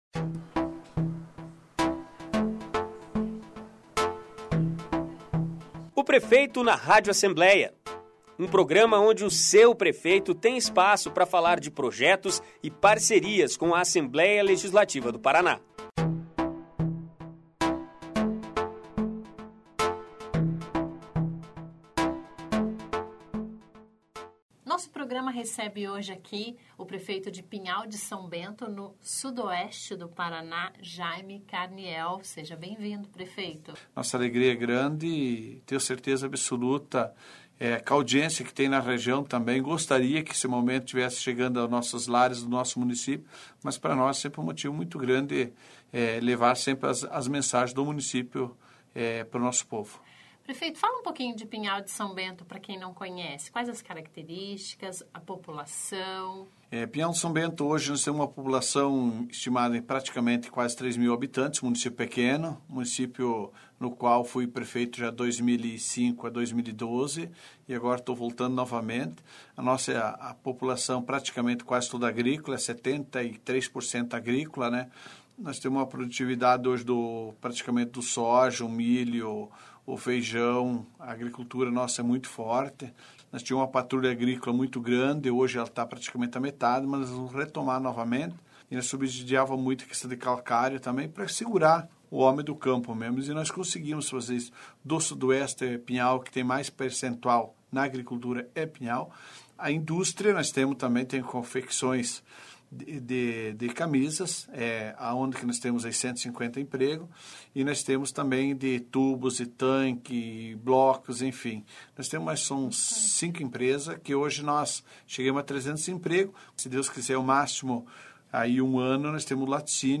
Ouça a entrevista com Jaime Carniel (PMDB), prefeito que assumiu o município do Sudoeste em janeiro deste ano.